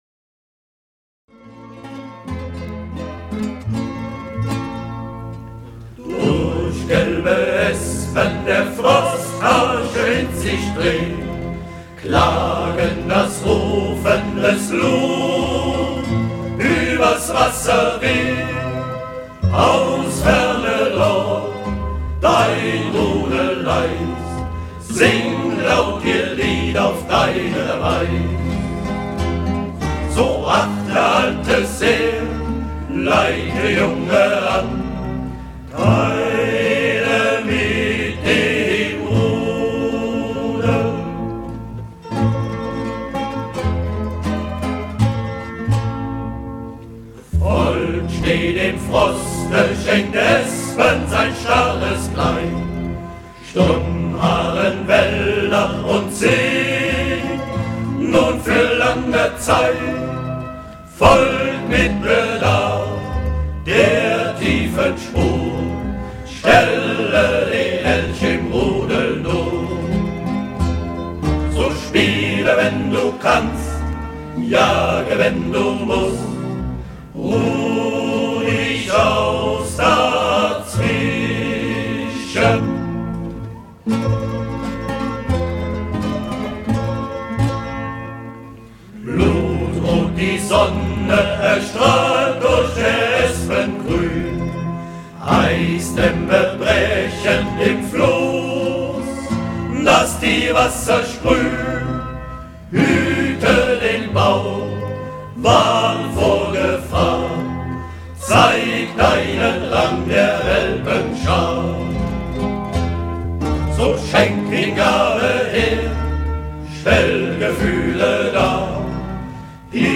Klagend